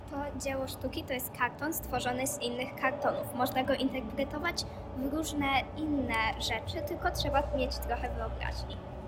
Obraz w ruchu. Interwencja: Audioprzewodnik